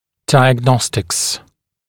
[ˌdaɪəg’nɔstɪks][ˌдайэг’ностикс]диагностика